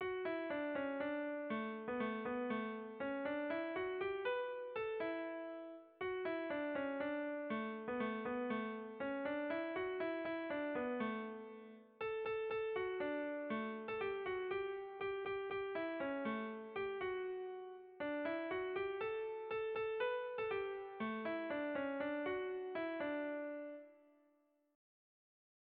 Irrizkoa
Doinu herrikoia, B. Lertxundik moldatua.
Zortziko handia (hg) / Lau puntuko handia (ip)
A1-A2-B-C